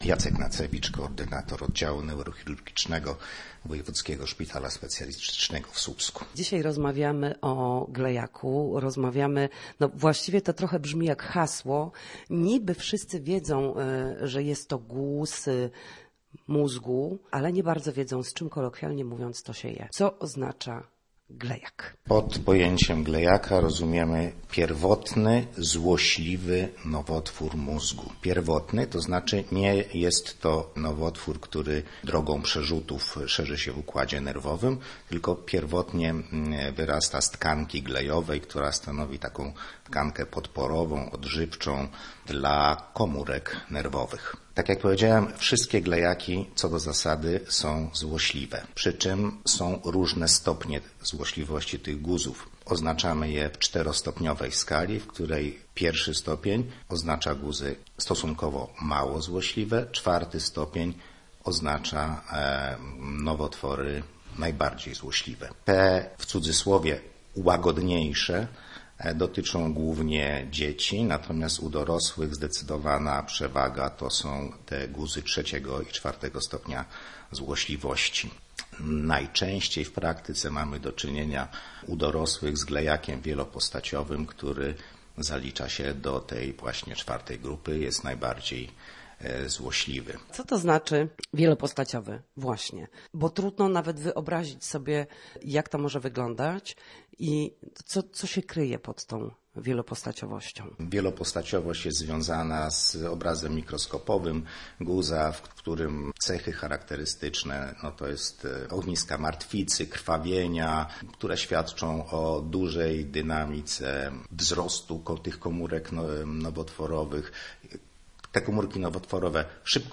W każdą środę, w popołudniowym paśmie Studia Słupsk Radia Gdańsk, dyskutujemy o tym, jak wrócić do formy po chorobach i urazach. W audycji „Na Zdrowie” nasi goście – lekarze i fizjoterapeuci – odpowiadają na pytania dotyczące najczęstszych dolegliwości.